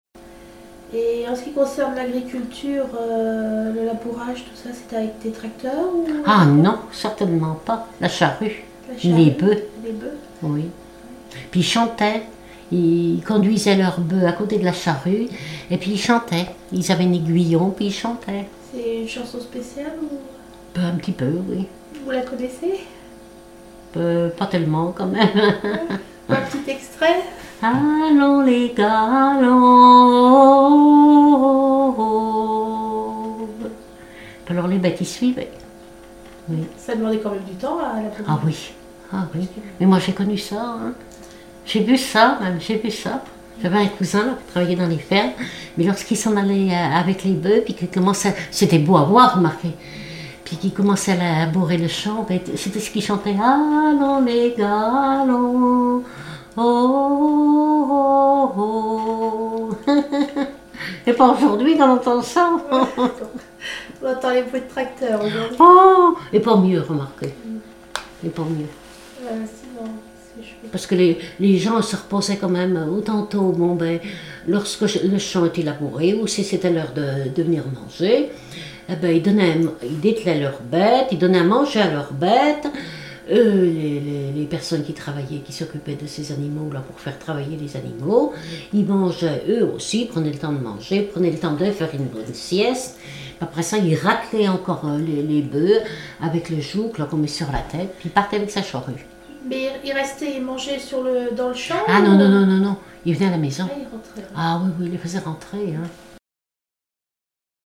essais de dariolage
labour, laboureur ; chanteur(s), chant, chanson, chansonnette ; traction bovine ;
Catégorie Témoignage